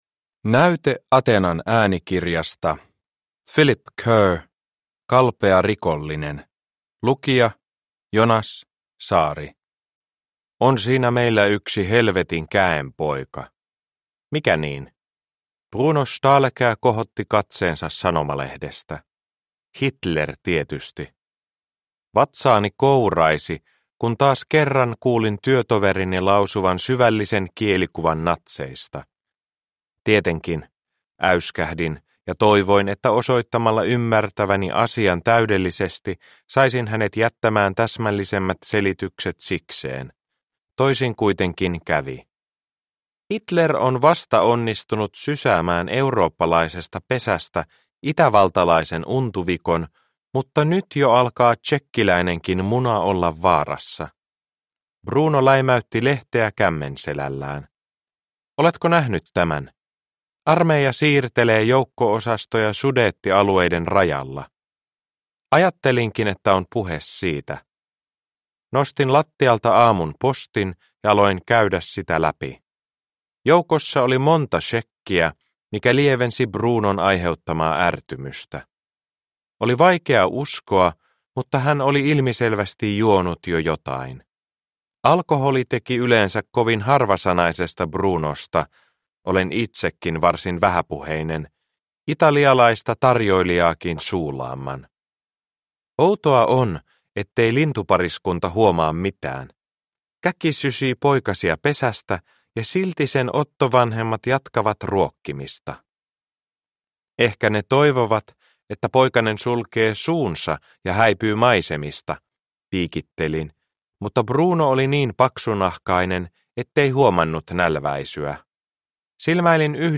Kalpea rikollinen – Ljudbok – Laddas ner